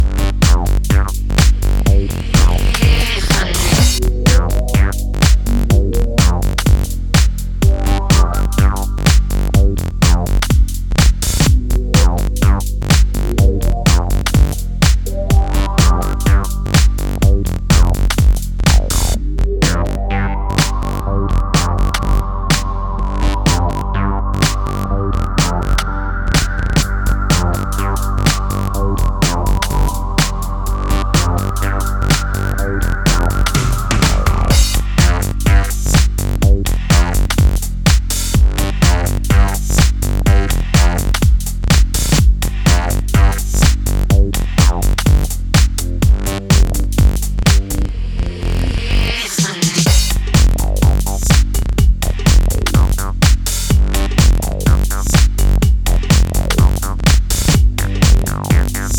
Simplicity in its purest form, with groove in mind, always.